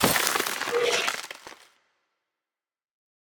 Minecraft Version Minecraft Version 1.21.5 Latest Release | Latest Snapshot 1.21.5 / assets / minecraft / sounds / mob / stray / convert2.ogg Compare With Compare With Latest Release | Latest Snapshot